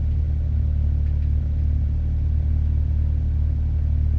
v6_02_idle.wav